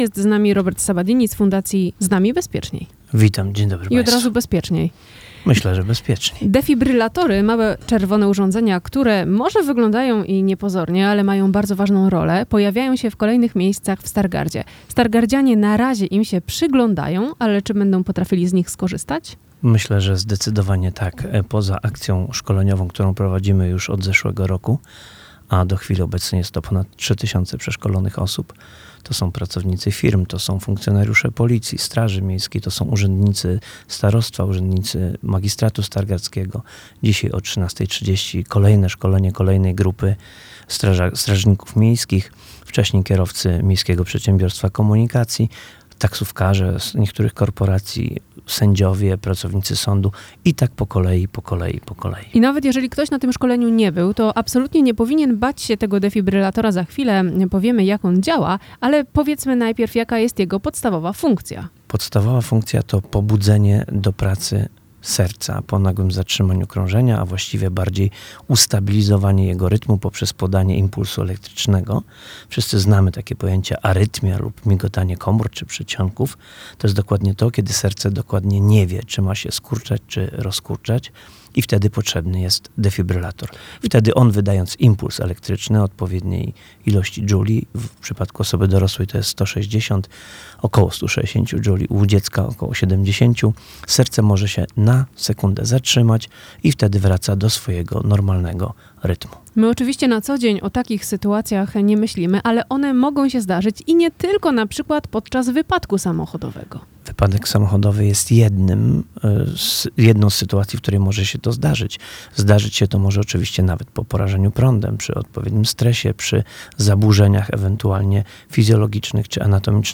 Cała rozmowa do odsłuchania tutaj: